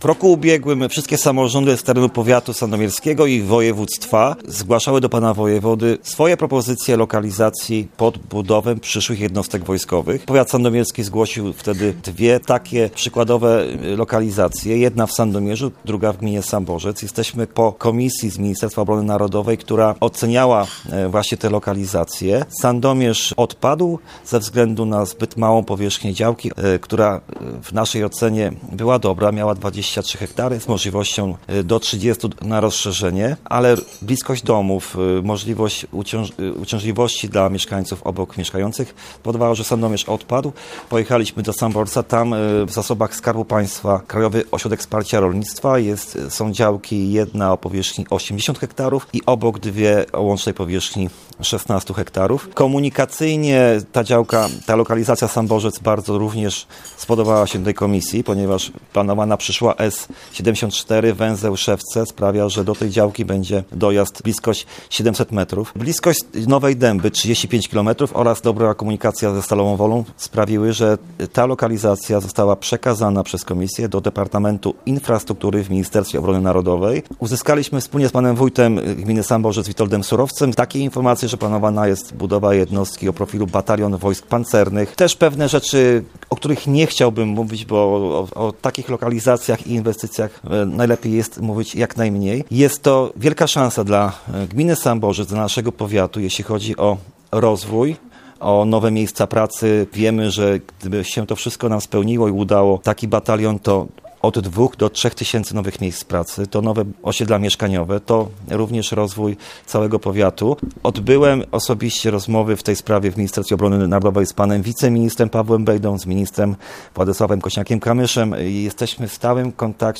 Baza wojsk pancernych może powstać na terenie powiatu sandomierskiego. Chodzi o teren w gminie Samborzec. O szczegółach na antenie Radia Leliwa mówił starosta sandomierski Marcin Piwnik: